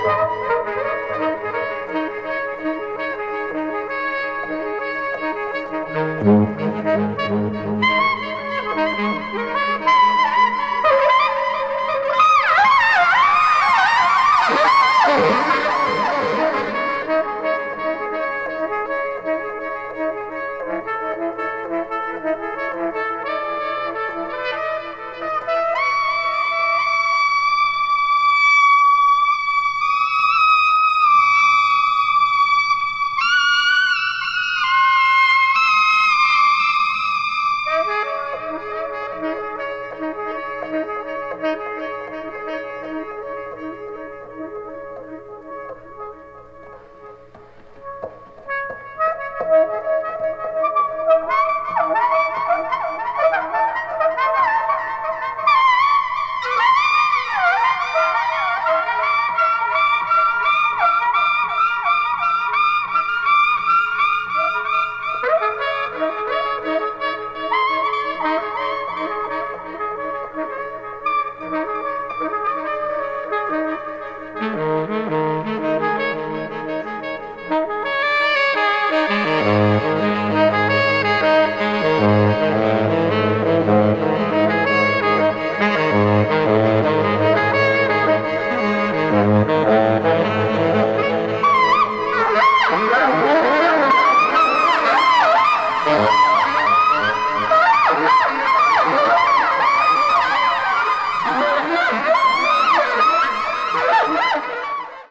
抜群の酩酊感！テープエコーを多用したジャズ～ドローン経由のミニマル・ミュージック！
陶酔と覚醒を同時に呼び込むジャズ～ドローン経由のミニマル・ミュージック！